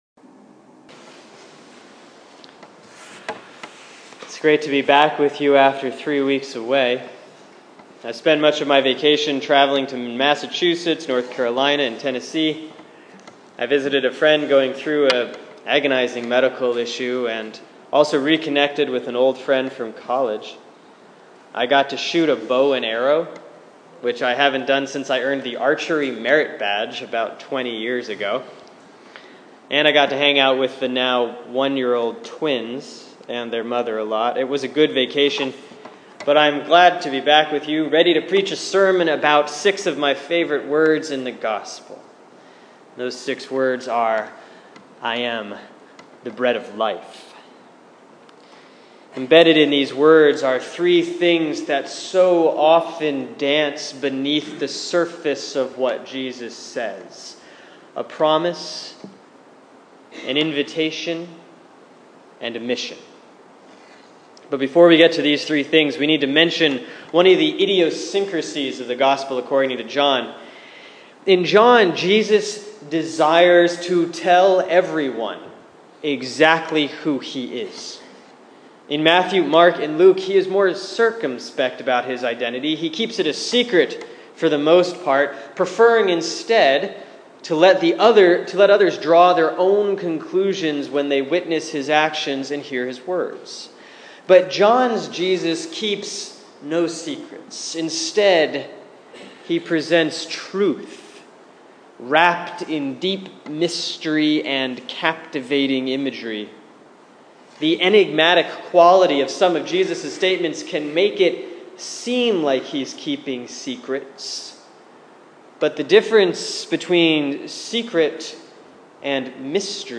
Sermon for Sunday, August 9, 2015 || Proper 14B || John 6:35, 41-51